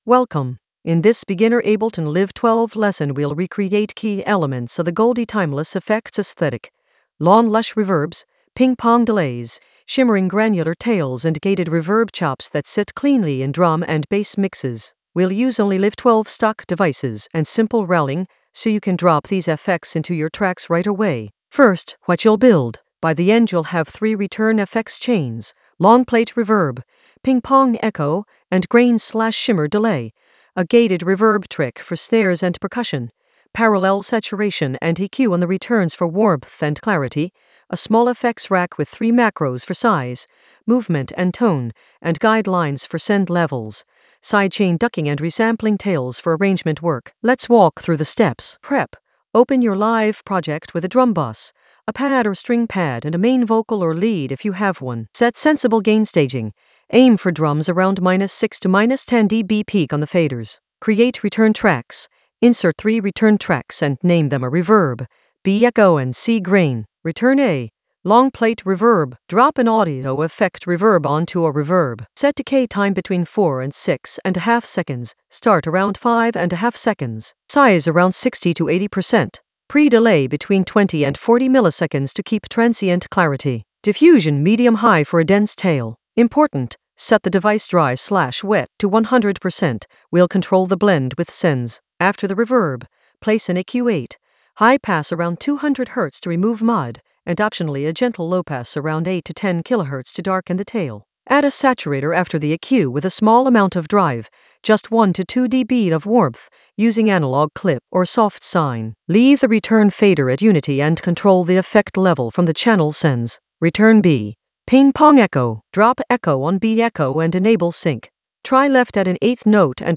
An AI-generated beginner Ableton lesson focused on goldie timeless effects in Ableton Live 12 in the FX area of drum and bass production.
Narrated lesson audio
The voice track includes the tutorial plus extra teacher commentary.